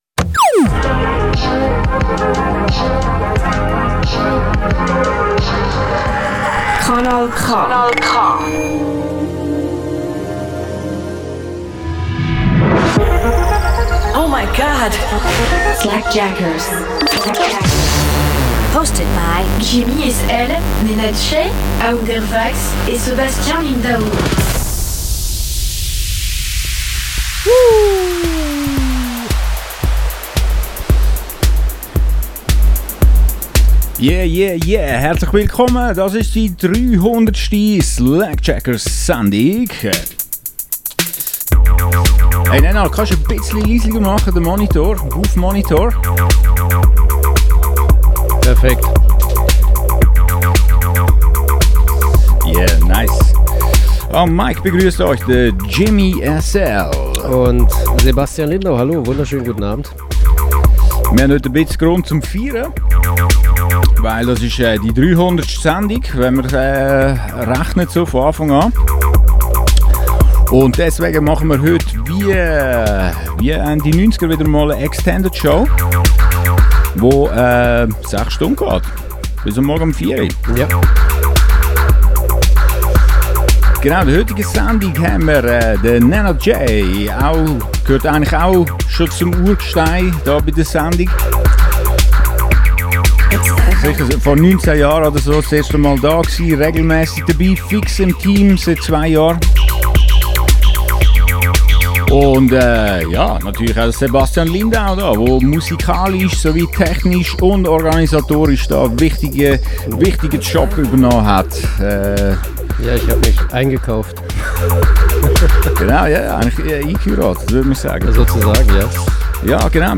Techno, Acid, Electro Radioshow